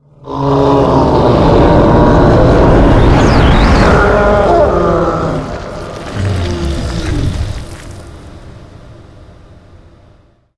spawners_mobs_balrog_death.ogg